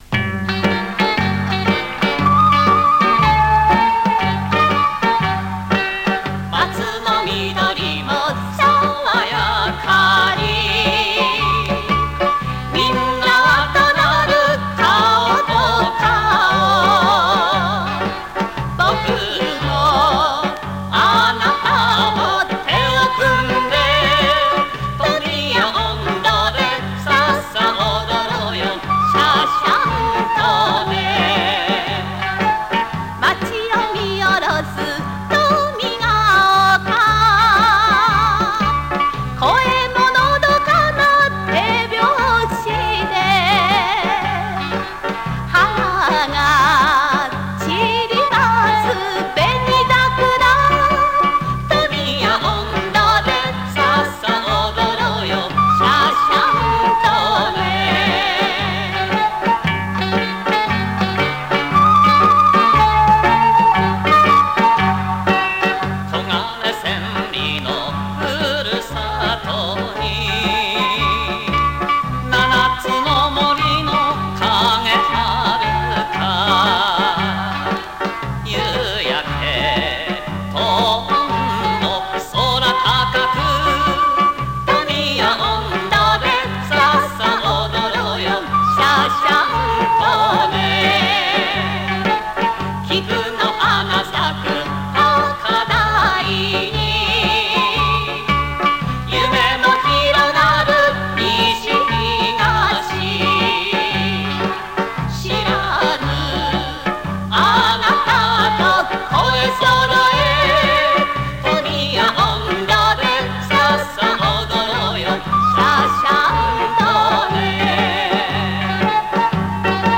今では市内各地で開催される祭りなどで受け継がれる、富谷の文化として皆さんに親しまれています。